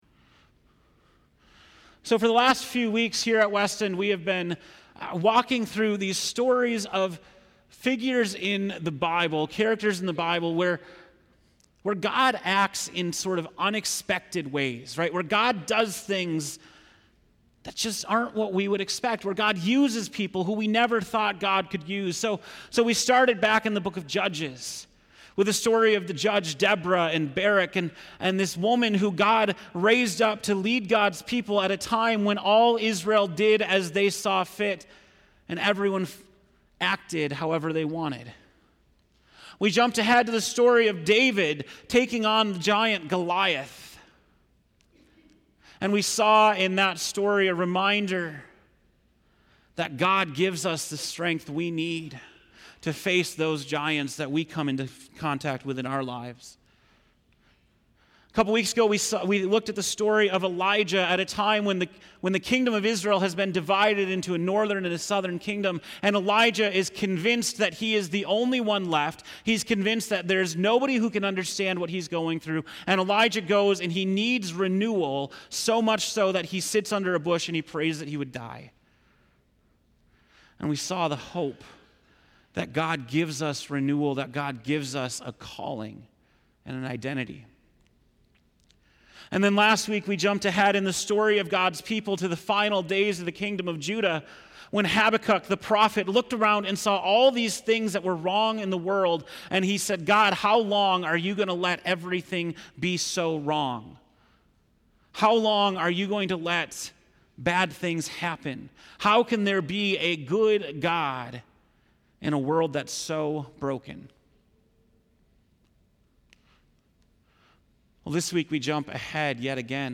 August 26, 2018 (Morning Worship)